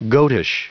Prononciation du mot goatish en anglais (fichier audio)
Prononciation du mot : goatish